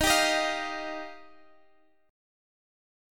Ebadd9 Chord
Listen to Ebadd9 strummed